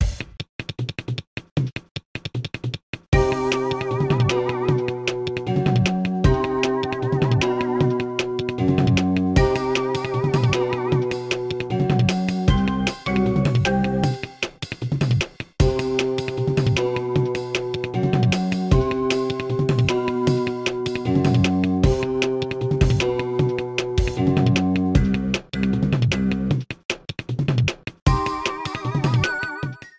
Boss music